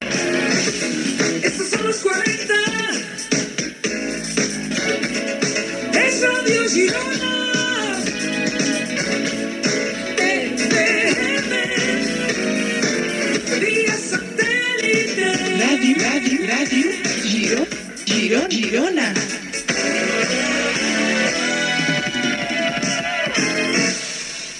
Indicatiu del programa de Ràdio Girona FM.
Musical